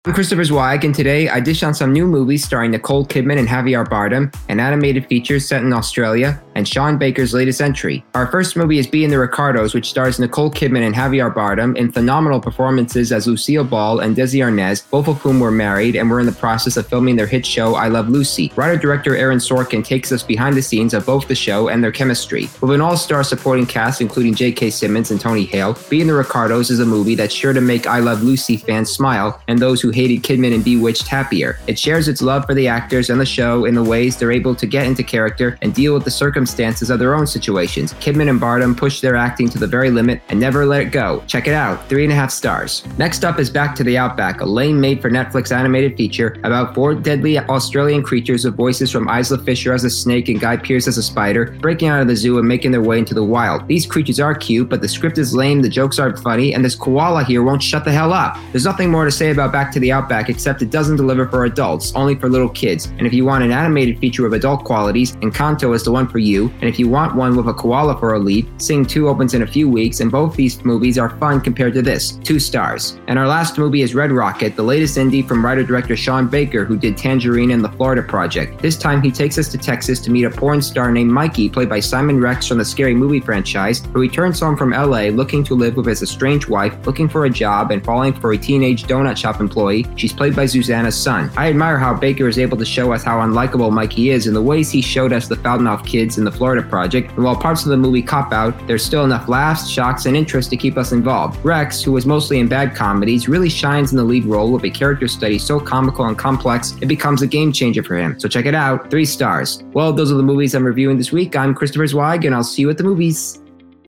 Being the Ricardos, Back to the Outback, and Red Rocket Podcast Reviews